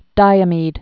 (dīə-mēd)